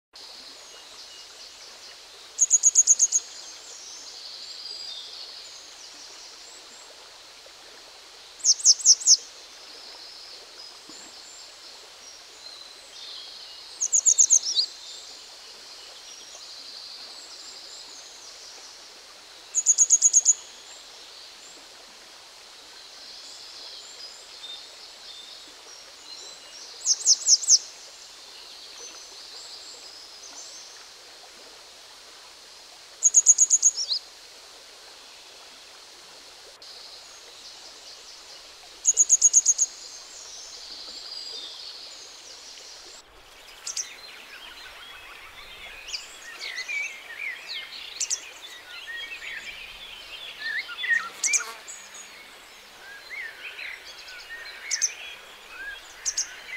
die genannten und abgebildeten Vögel sind im Park anzutreffen
Gebirgsstelze
Gebirgsstelze.mp3